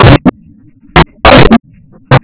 تنويه : نعتذر عن عدم جودة تسجيل بعض الأناشيد
دف